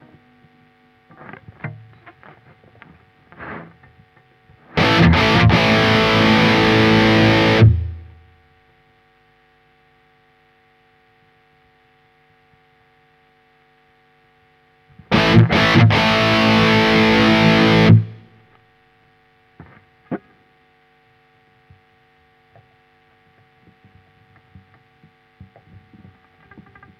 Ich habe allerdings festgestellt, dass die Profile mit High Gain ganz schön brummen. Ich habe 2 Gitarren mit Humbucker. Es brummt trotzdem.
Das Profil ist Diesel VH Four. Nosie Gate ist aus auch am Input.